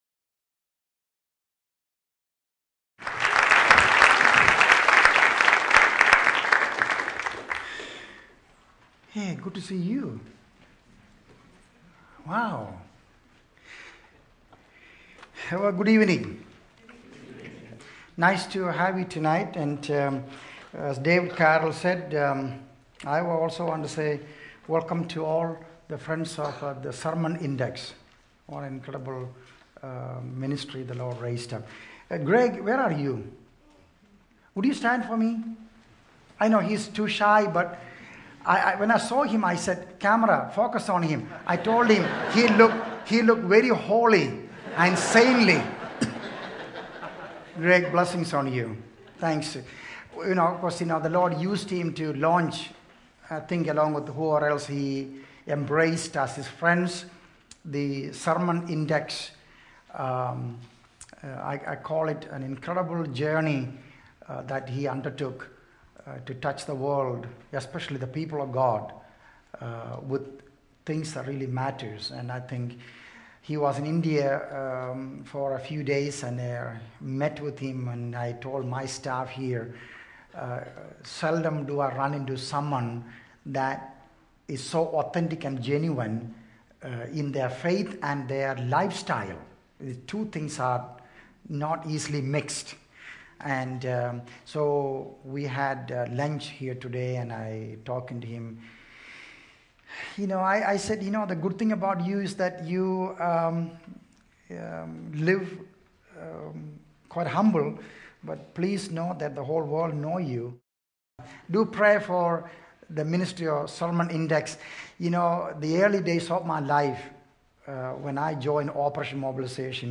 In this sermon, the speaker discusses the story of Moses and his call to lead the Israelites out of Egypt. The speaker emphasizes how Moses initially felt inadequate and unworthy for the task, but God assured him that He had seen the suffering of His people and was concerned about their well-being.